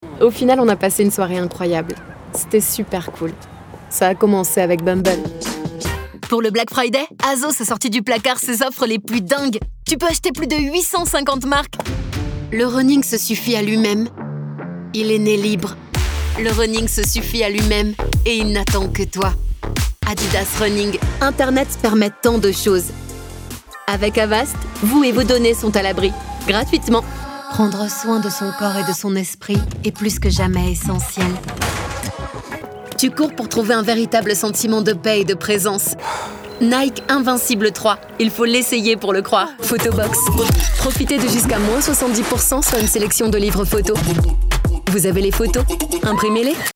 Accessible, Warm, Playful, Natural, Friendly
Commercial